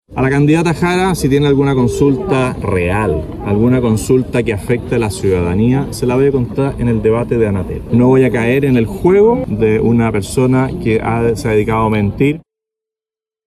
En el lugar, realizó un punto de prensa donde fue abordado por las declaraciones del diputado republicano José Meza -en torno a conmutar penas a reos con enfermedades terminales, incluyendo a abusadores de menores- y el emplazamiento realizado por Jara a responder por estos dichos.